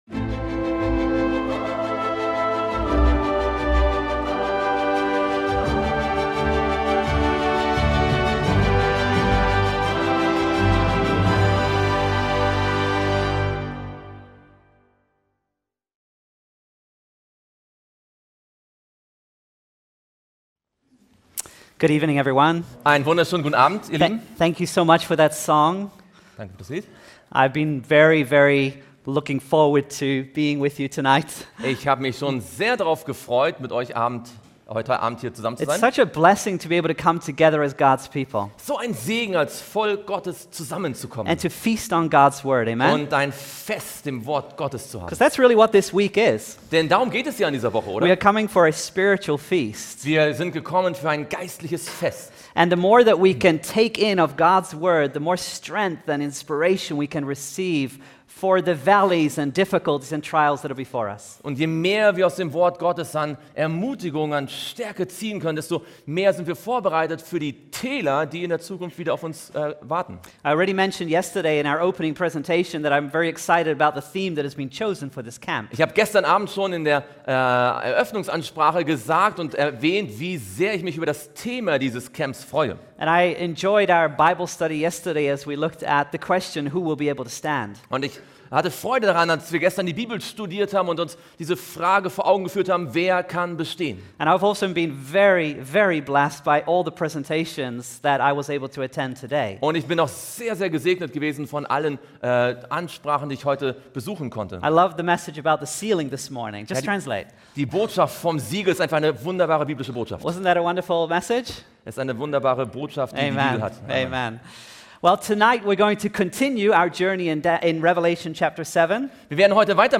In diesem aufschlussreichen Vortrag wird erläutert, wie die 144.000 in der Offenbarung symbolisch für das Volk Gottes in der Endzeit stehen. Durch die tiefgehende Analyse biblischer Namen und deren Bedeutungen wird die spirituelle Reise und Erfahrung beschrieben, die diese Menschen durch Prüfungen und mit Freude im Herzen machen werden.